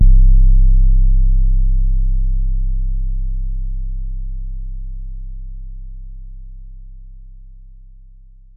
808 (Shake The Room).wav